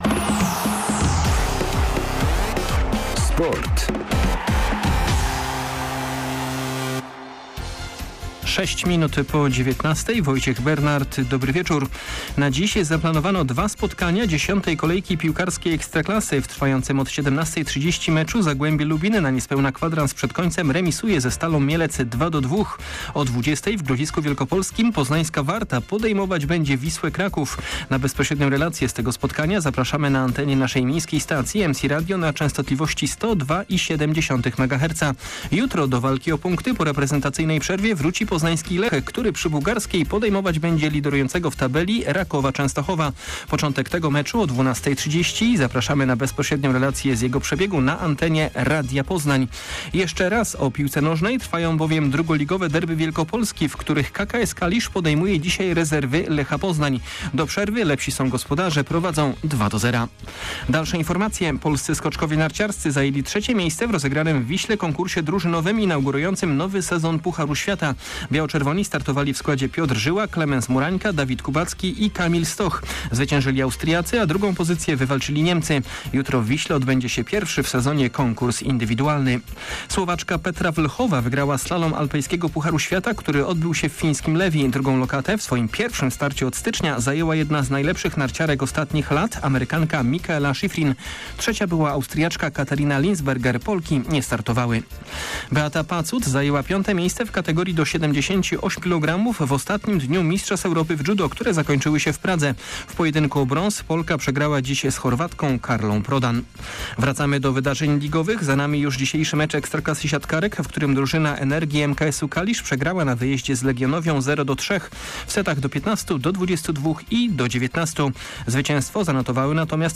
21.11. SERWIS SPORTOWY GODZ. 19:05